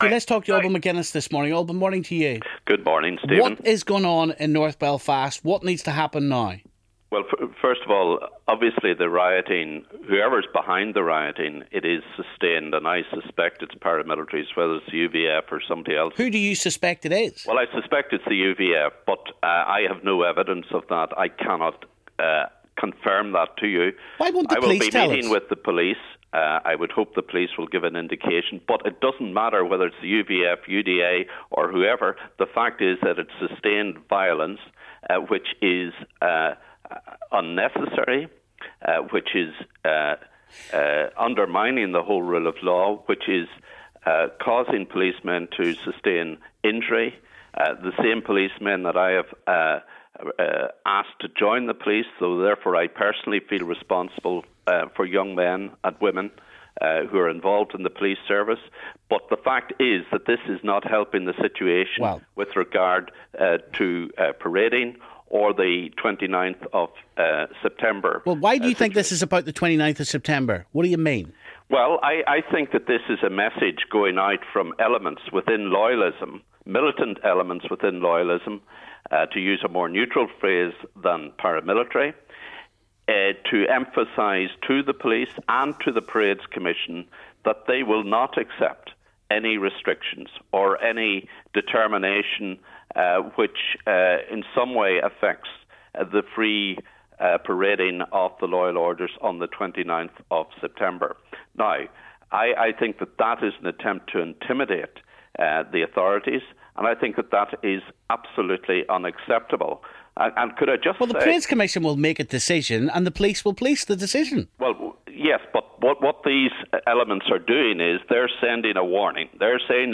SDLP MLA Alban McGuinness says it does not matter who is behind this, the violence undermines the rule of law.